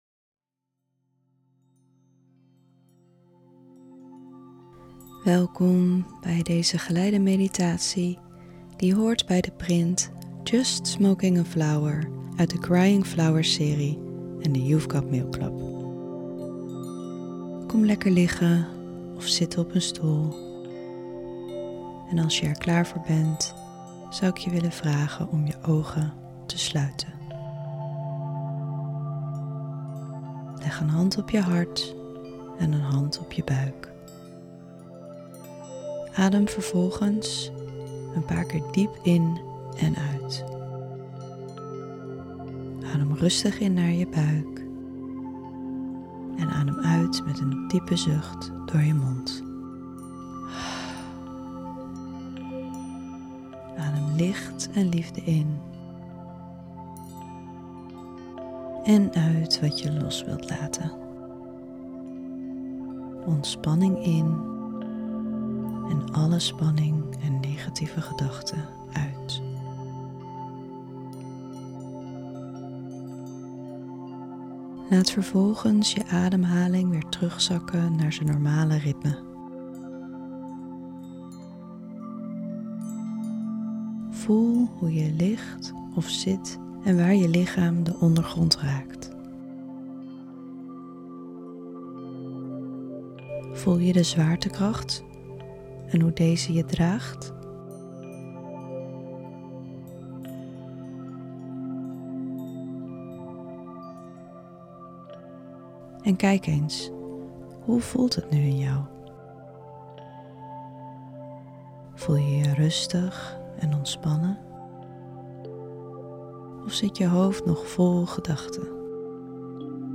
Als je goed luistert kun je haar in de begeleide meditatie horen fluisteren.